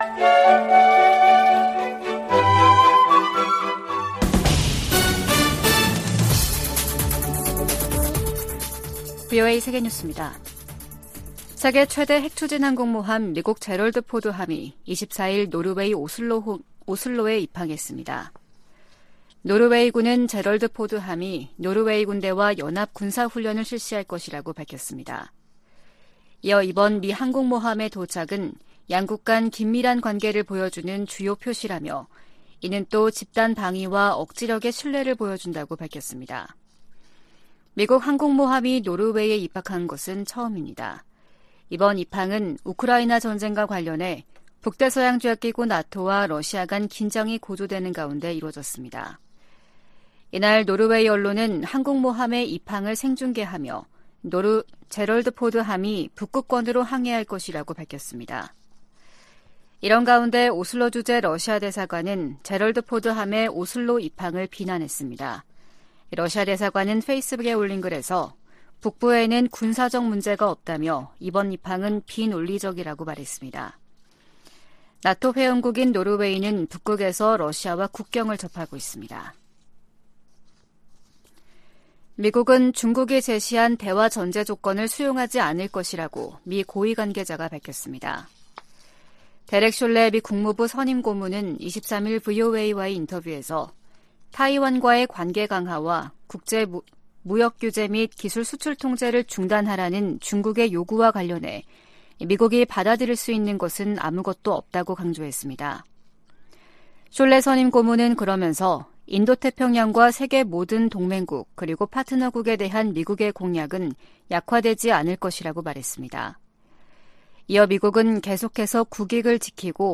VOA 한국어 아침 뉴스 프로그램 '워싱턴 뉴스 광장' 2023년 5월 25일 방송입니다. 미 재무부가 북한의 불법 무기 프로그램 개발에 필요한 자금 조달과 사이버 활동에 관여한 기관 4곳과 개인 1명을 제재했습니다. 북한이 27년 연속 미국의 테러 방지 노력에 협조하지 않는 나라로 지정됐습니다.